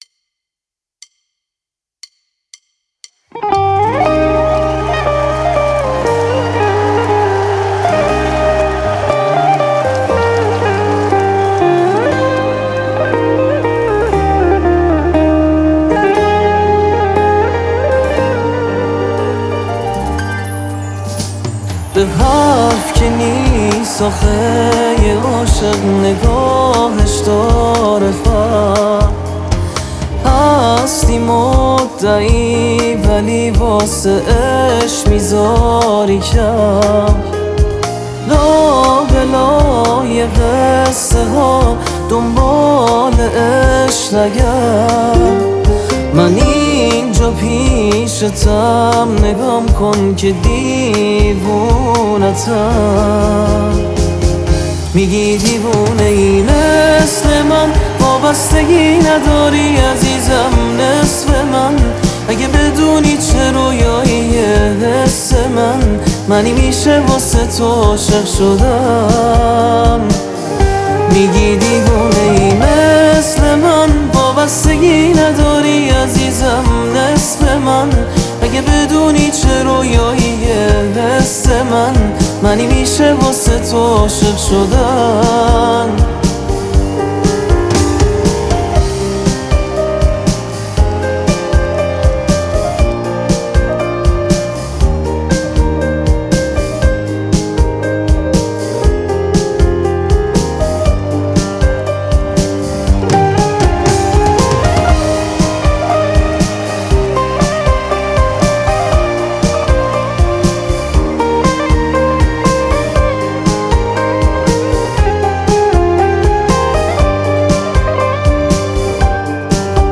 آهنگ ایرانی آهنگ پاپ